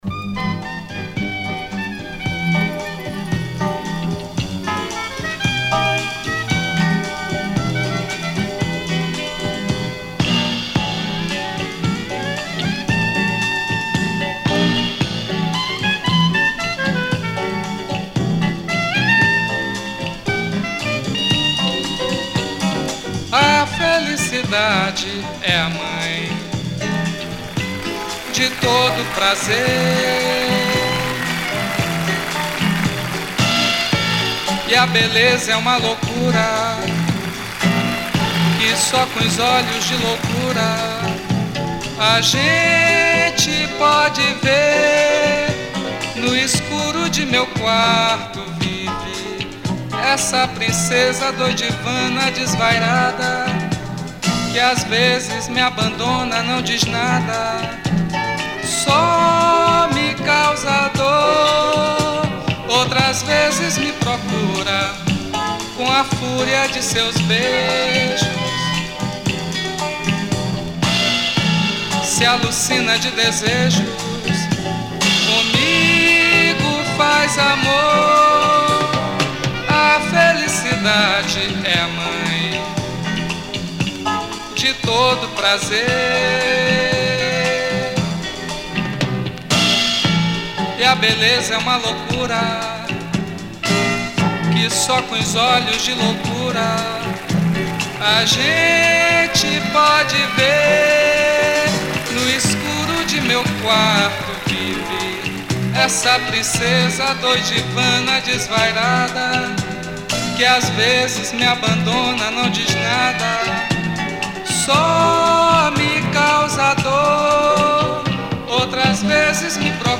119      Faixa:     Mpb